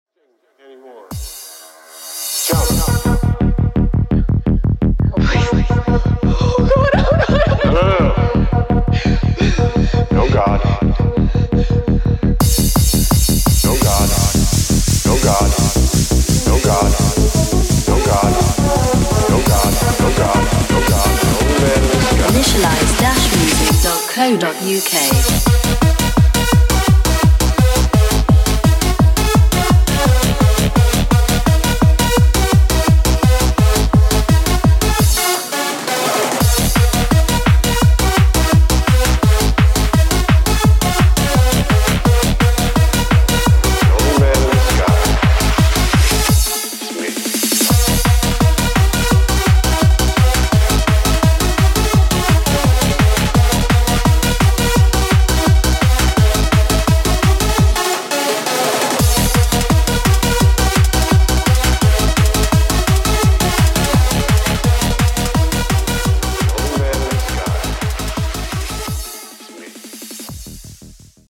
makina